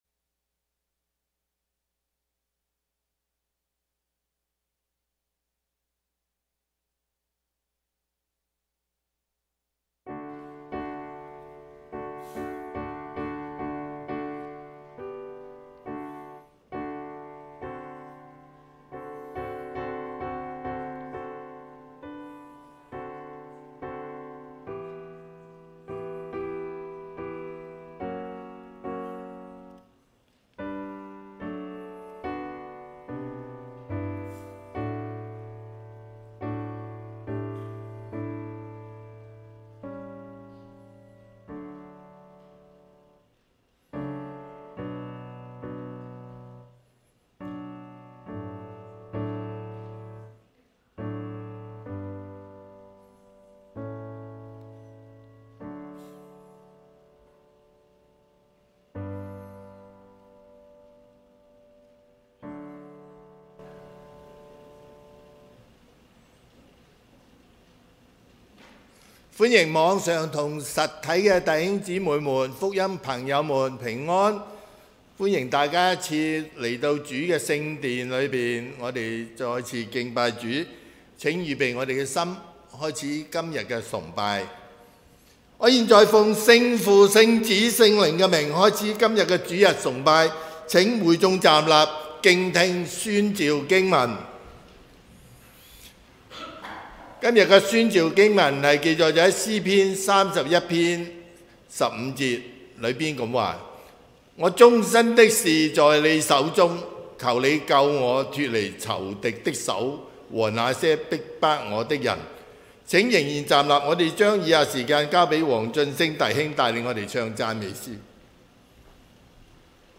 2026年2月8日溫城華人宣道會粵語堂主日崇拜